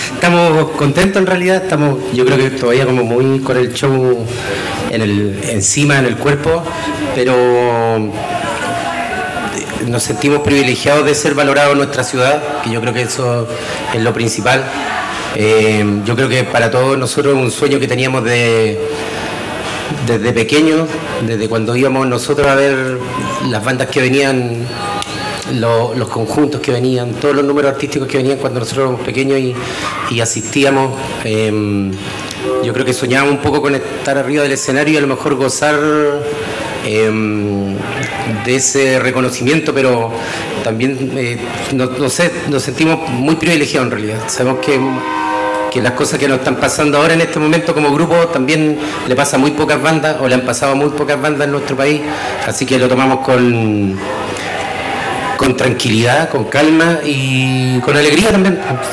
Al término de su presentación, el quinteto se acercó hasta la carpa de prensa para conversar con los medios acreditados acerca de este show y el impacto del retorno a Concepción un año después del comienzo de la gira “Ven Aquí”.